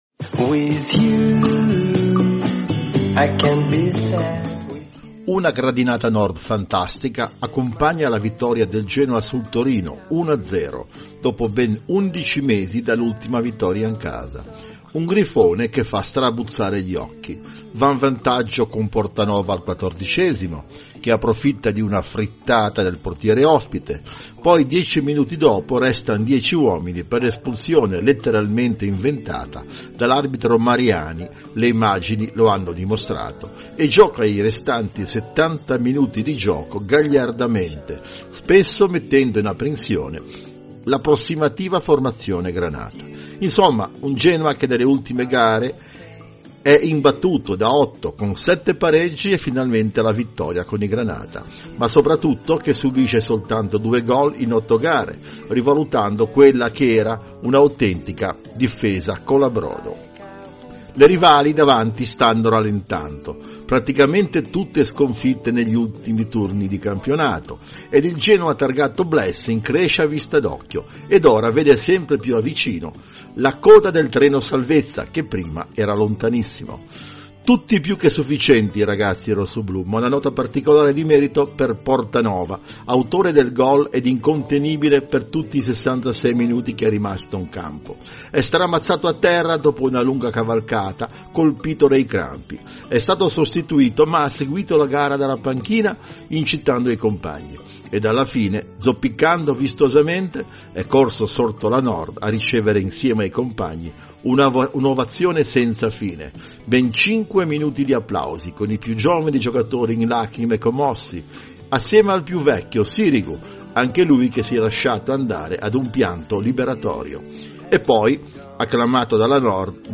Audio I Genoa - Torino, il commento a freddo del giorno dopo - Liguria Notizie